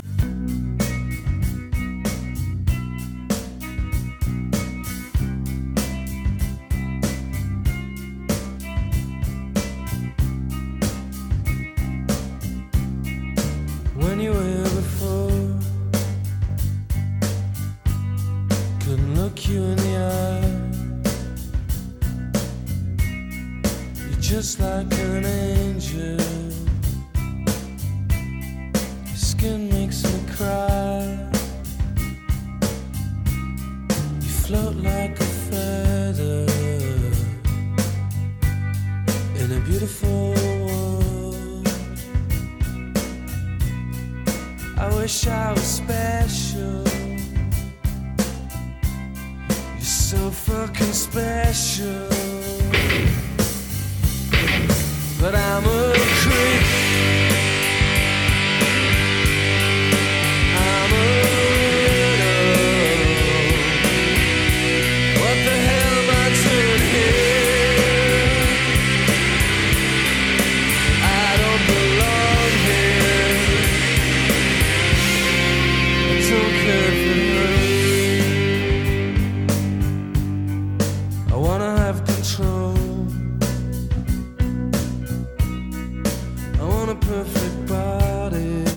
English rock-band